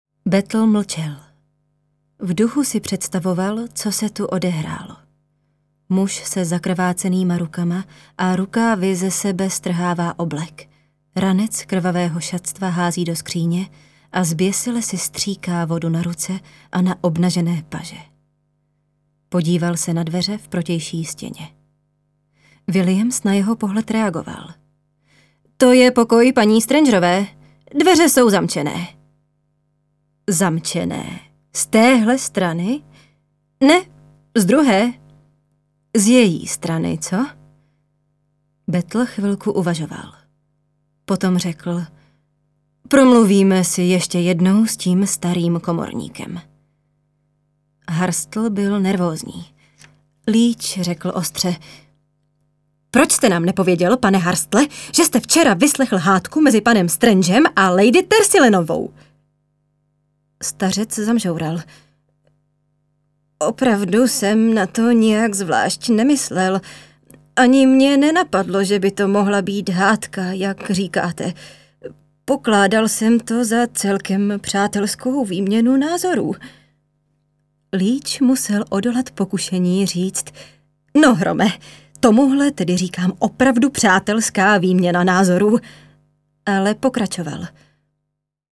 ukázka audio kniha
ukazka-audio-kniha.mp3